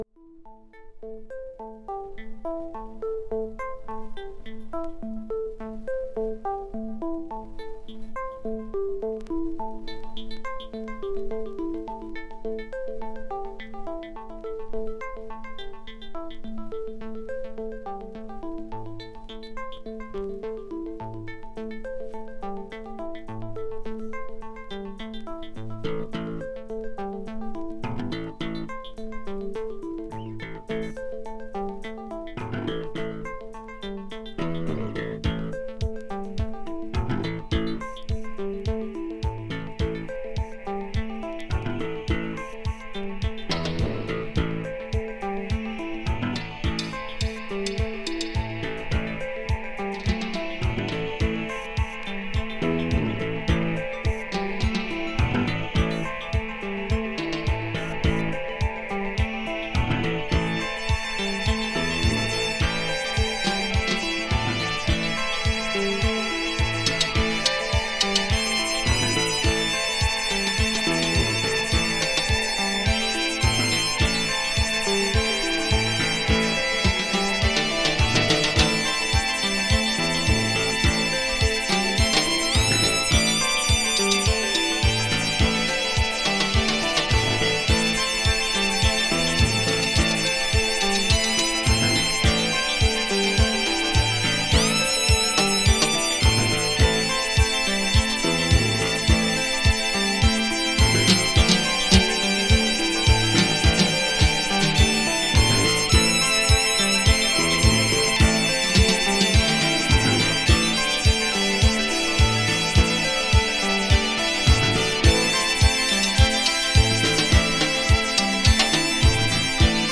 German Deep Electronics!
」をはじめ、ディープ&コズミックなエレクトロニクス・トラックを多数収録！